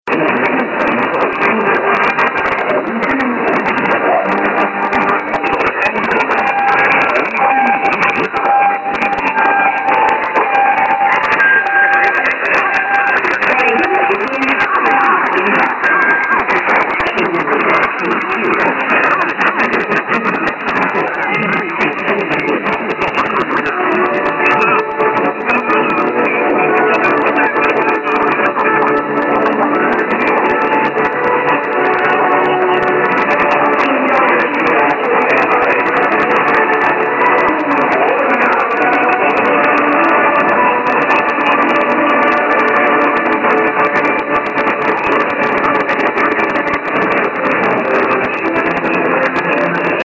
As a comparison, here's how 1035 sounded at 1400 UTC during a DXpedition to Grayland WA several years ago. I counted 4-5 sets of pips & time checks, making for a wild mess.